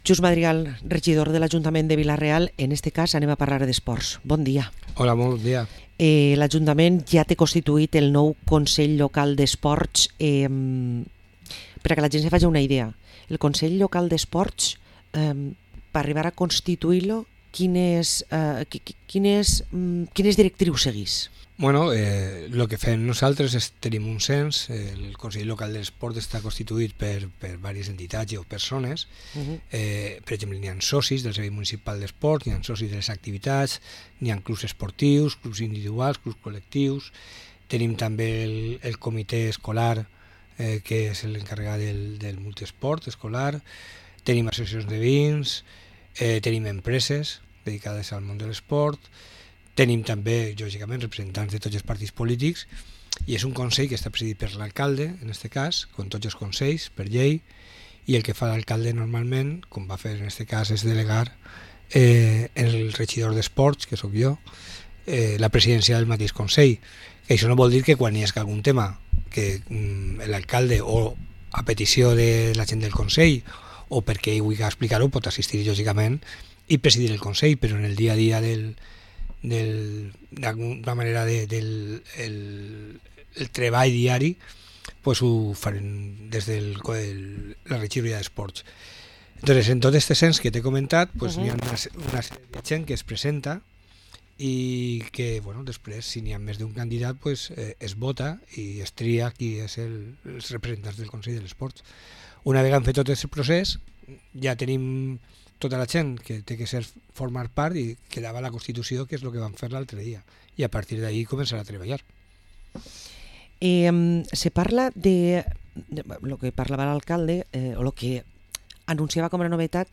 Parlem amb el regidor Xus Madrigal